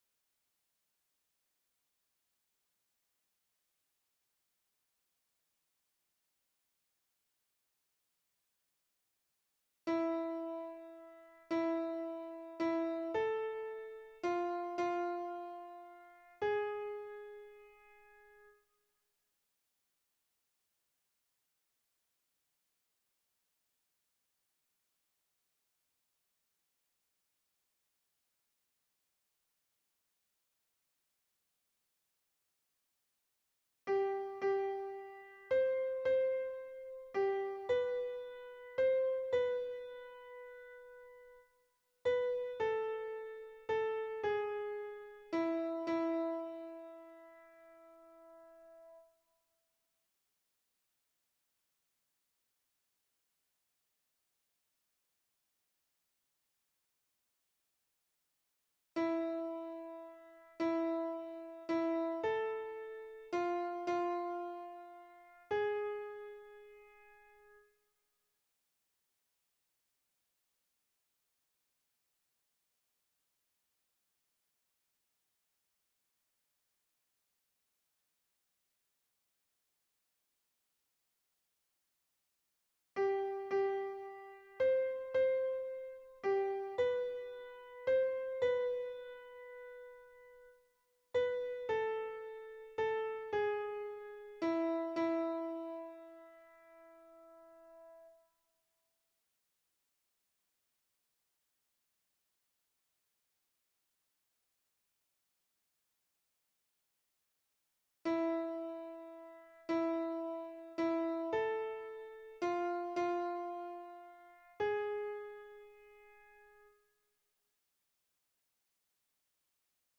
- Oeuvre pour choeur à 3 voix mixtes